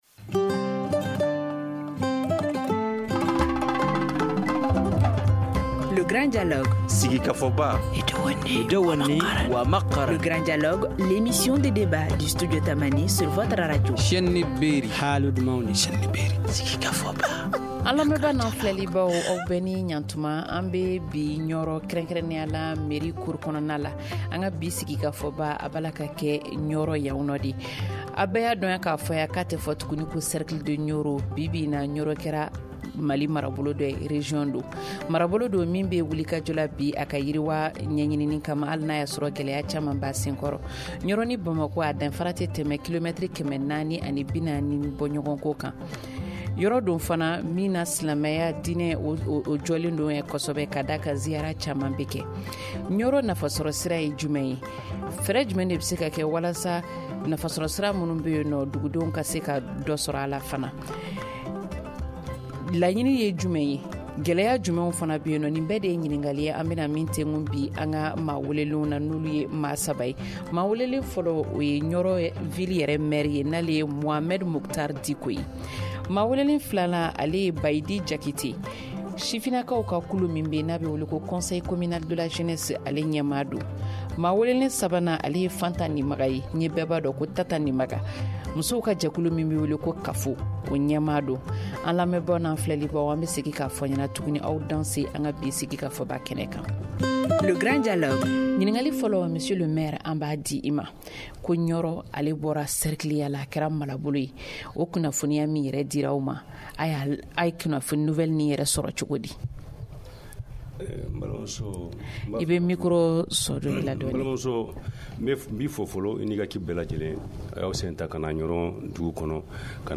Quels sont les besoins, les attentes et les difficultés des Niorois ? Autant de questions que nous allons décortiquer avec nos invités :